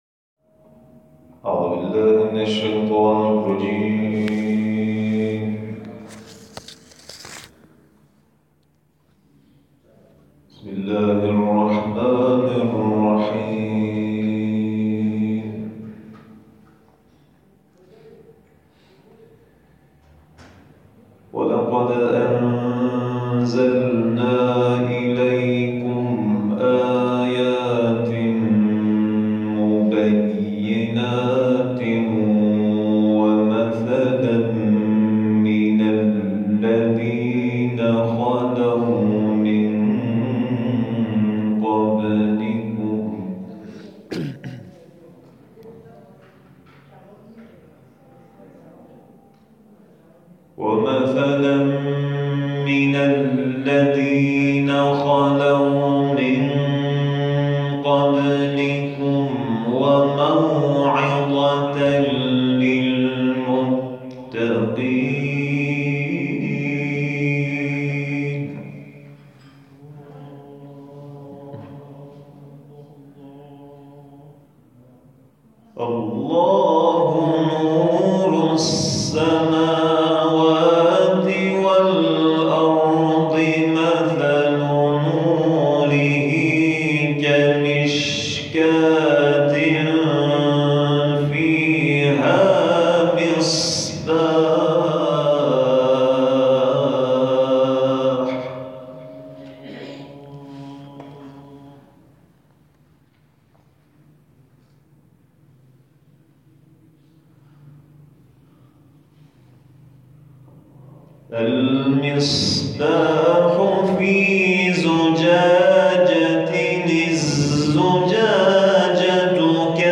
جلسه آموزشی حفظ و قرائت قرآن مؤسسه کریمه برگزار شد+ تلاوت